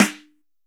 B.B SN 7.wav